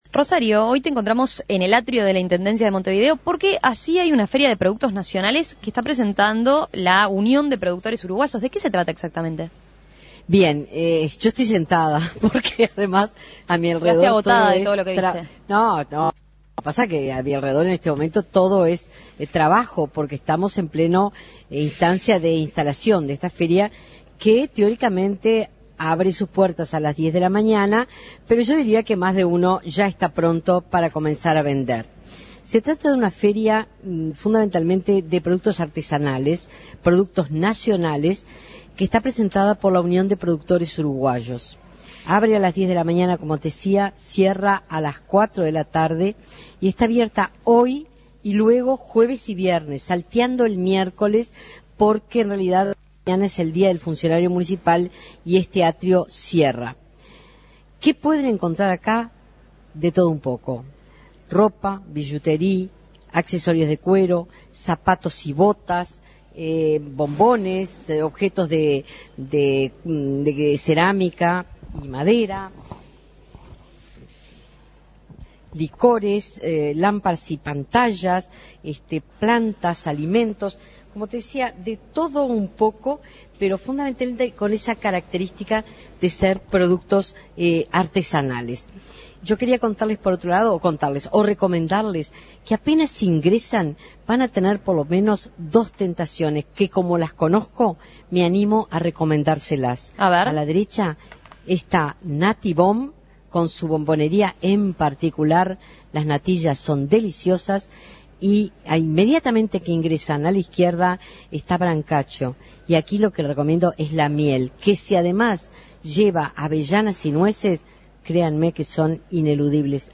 Feria de productos nacionales presentada por la Unión de Productores Uruguayos en el Atrio de la Intendencia Municipal